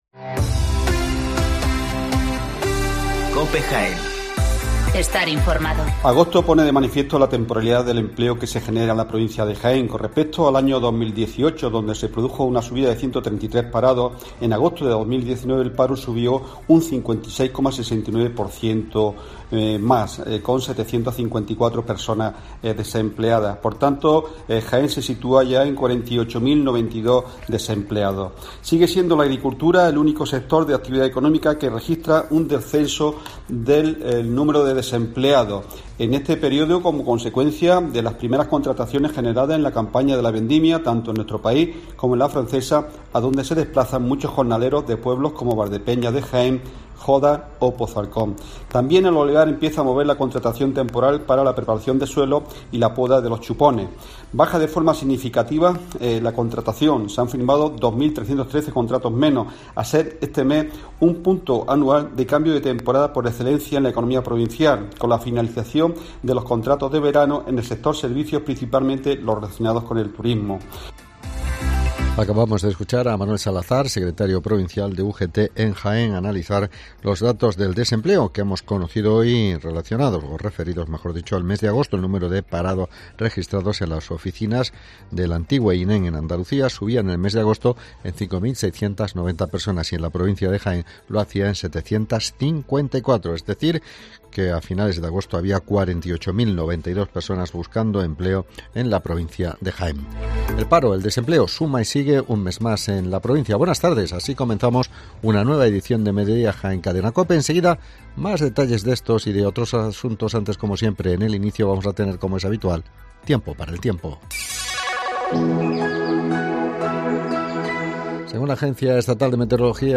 Toda la actualidad, las noticias más próximas y cercanas te las acercamos con los sonidos y las voces de todos y cada uno de sus protagonistas.